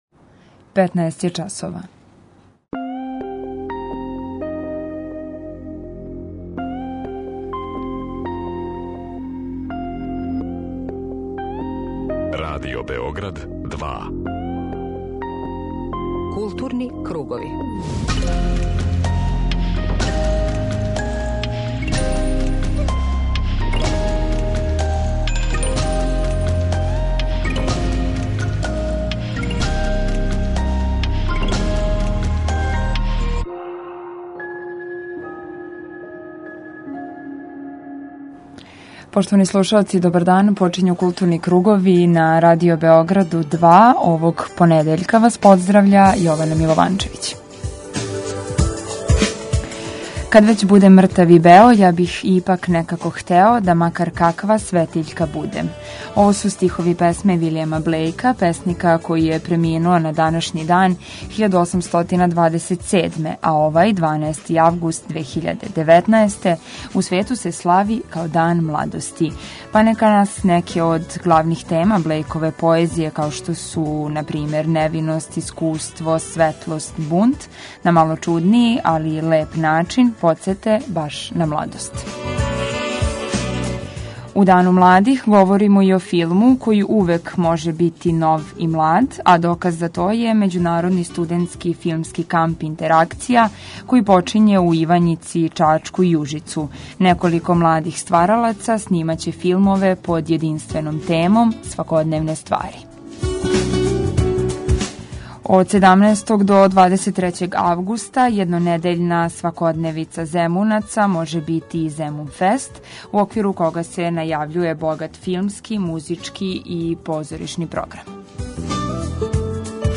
Магазин културе Радио Београда 2